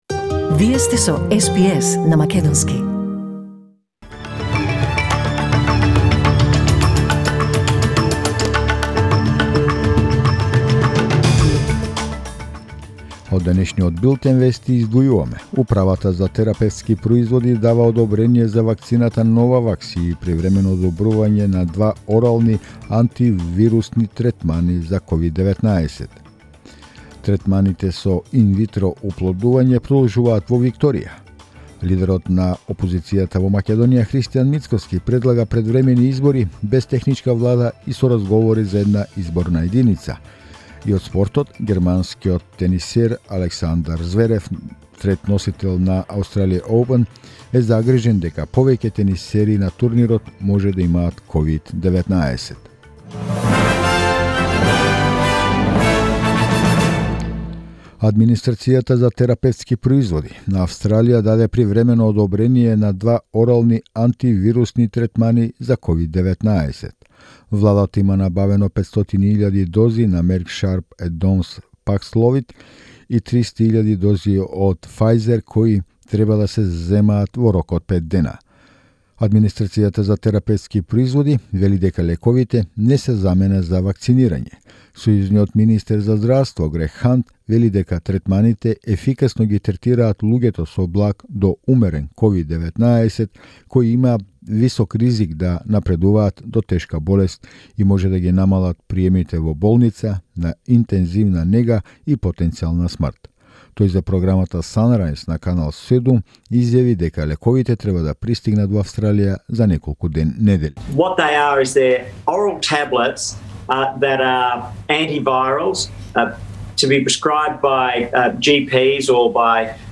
SBS News in Macedonian 20 January 2022